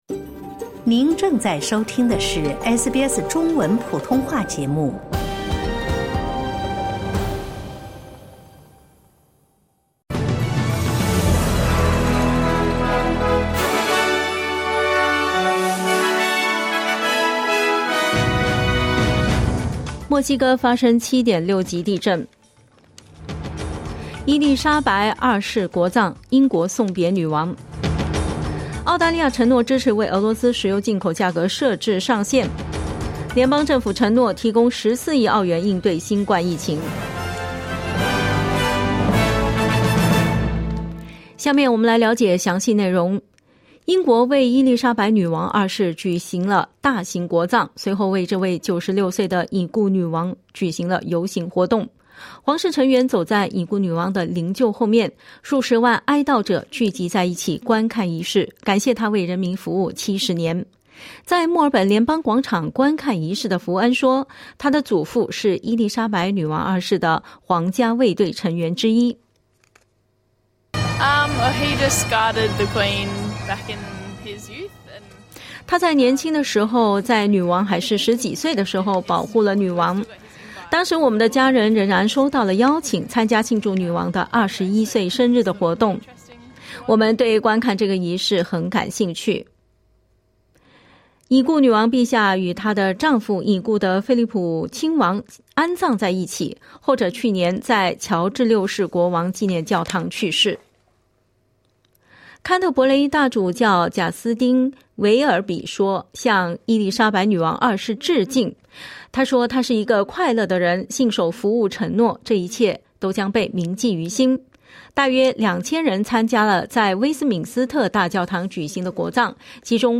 SBS早新闻（9月20日）